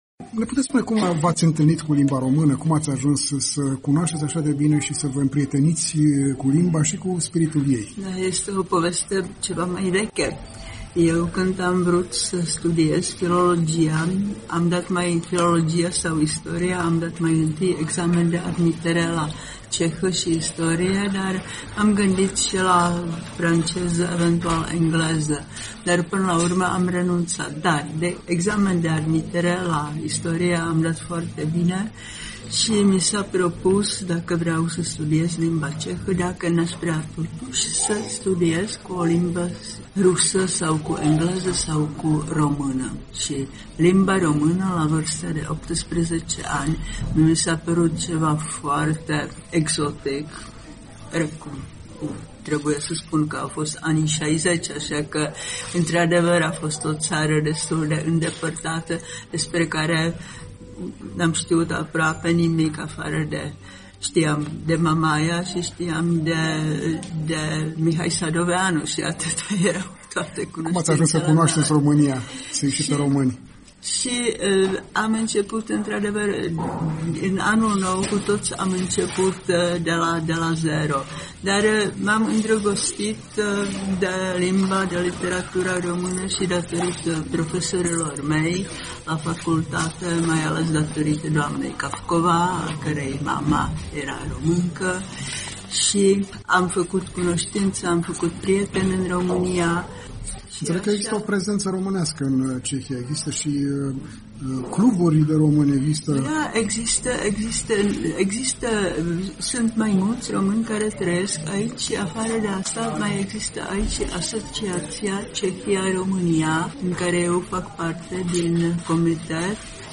Luna lecturilor de autor - festivalul de la Brno, 2019